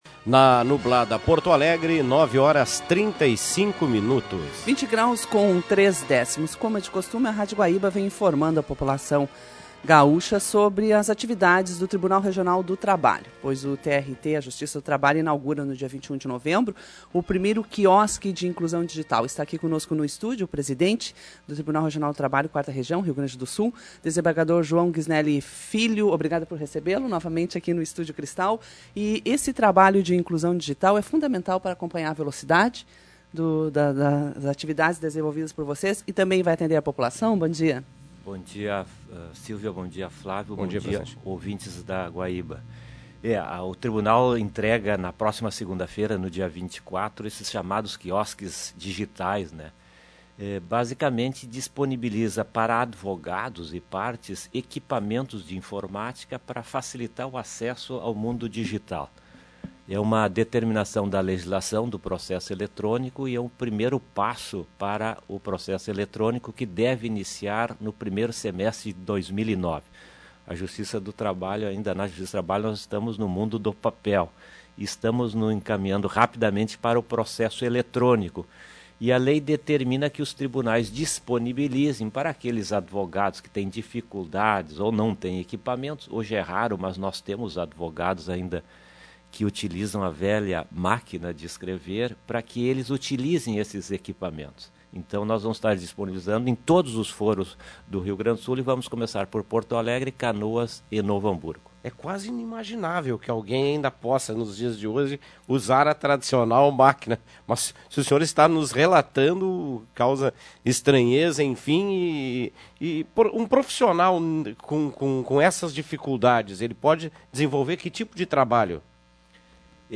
Rádio Guaíba AM: Presidente concede entrevista